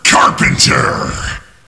SOUNDS: Add revised announcer sounds